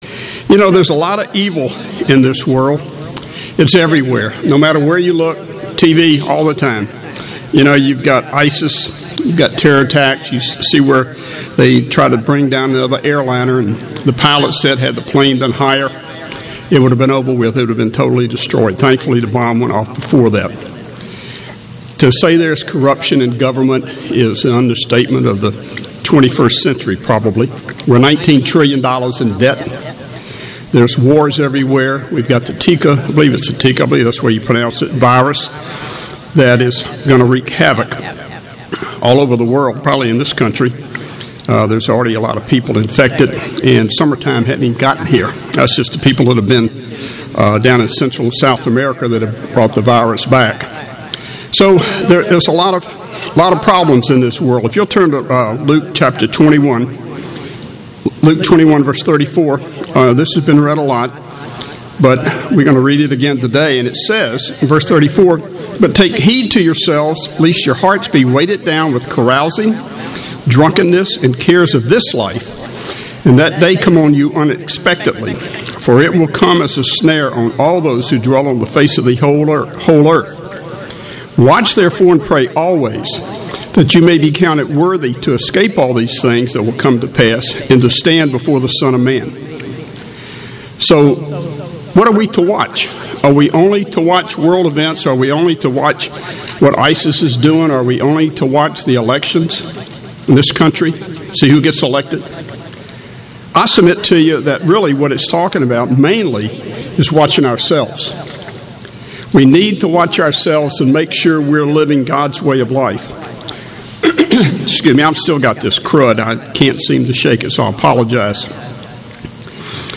Given in Atlanta, GA
UCG Sermon Studying the bible?